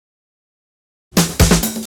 Fill 128 BPM (2).wav